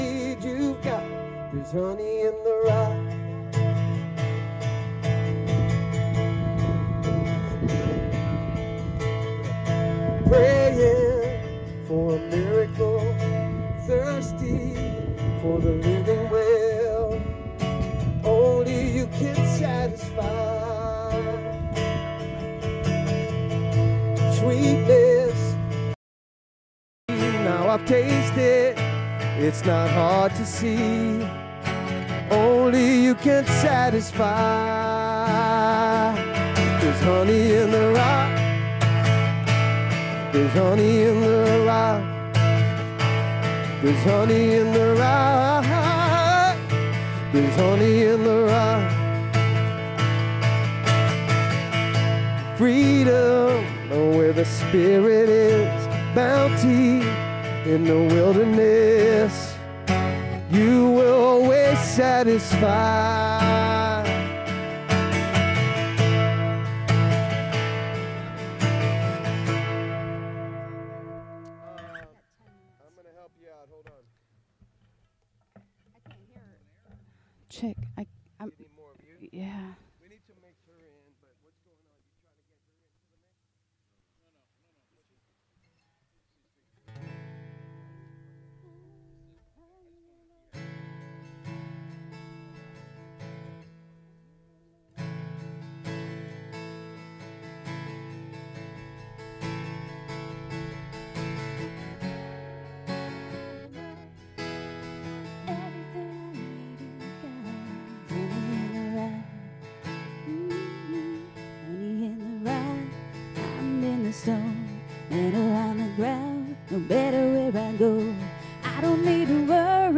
SERMON DESCIPTION God showed up with saving grace, calling us to live boldly in His presence through Jesus.